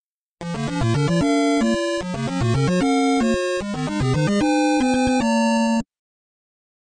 accomplish-wrong-warp.mp3